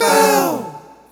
Hip Vcl Kord 1-A.wav